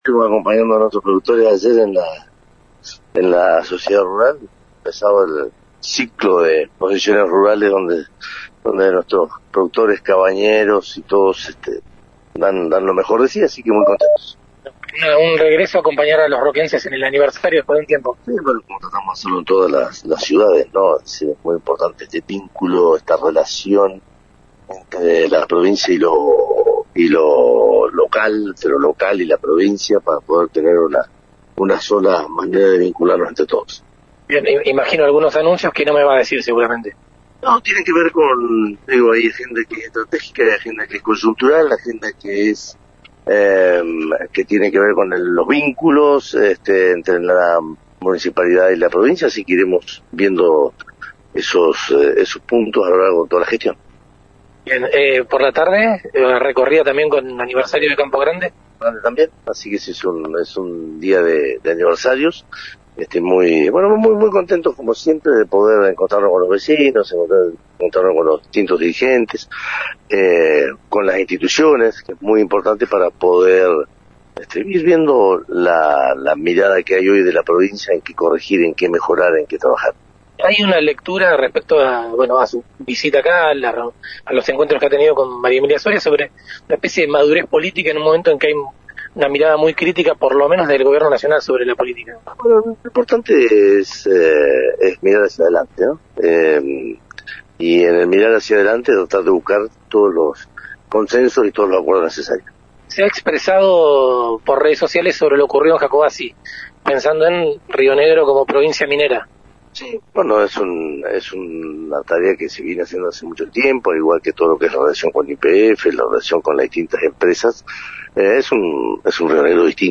En diálogo con Lu 19, el gobernador destacó la agenda estratégica, coyuntural, con los vínculos entre la municipalidad y la provincia. Además, remarcó el reencuentro con los vecinos, entre otros temas.